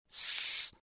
2. En español existe un solo fonema /s/, que se representa físicamente mediante dos sonidos: un sonido en que no vibran las cuerdas vocales [s]